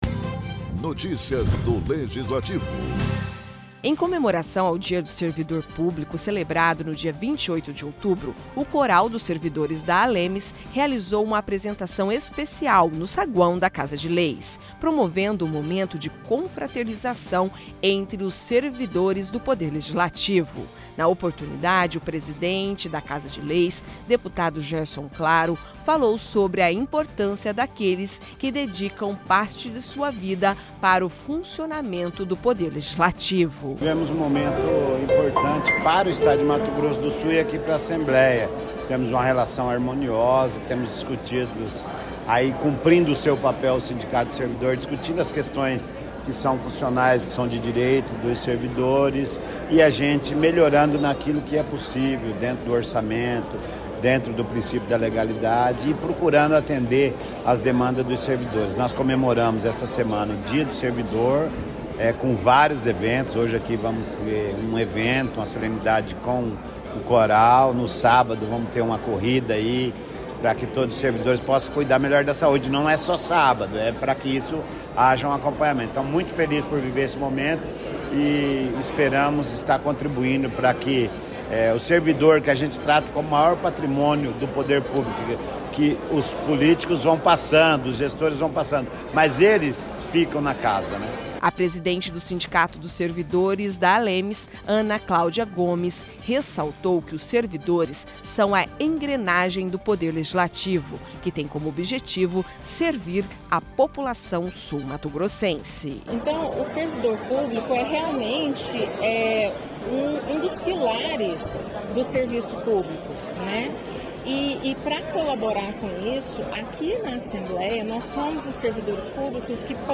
Apresentação do Coral da ALEMS marca comemorações do Dia do Servidor
Em comemoração ao Dia do Servidor Público, celebrado no dia 28 de outubro, o Coral dos Servidores da Assembleia Legislativa de Mato Grosso do Sul (ALEMS) realizou uma apresentação especial, no saguão da Casa de Leis, promovendo um momento de confraternização entre os servidores da Casa de Leis.